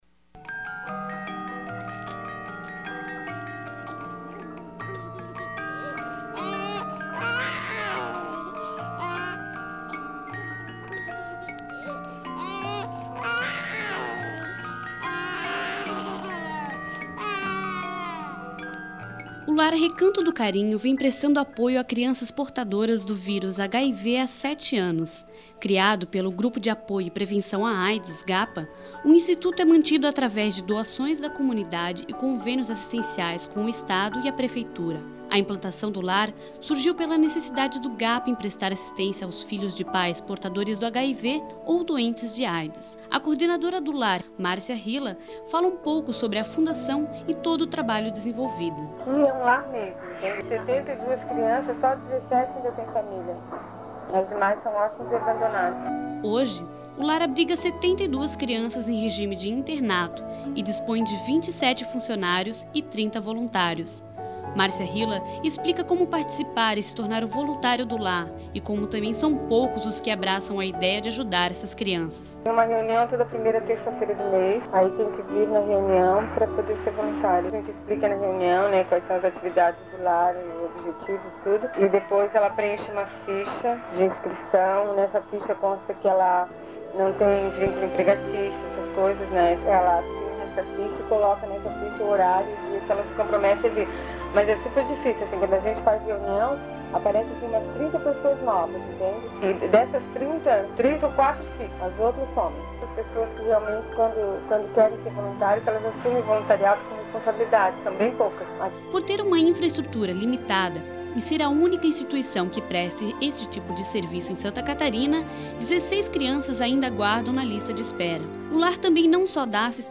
Abstract: Reportagem sobre a fundação Recanto do Carinho, lar de crianças portadoras do vírus HIV.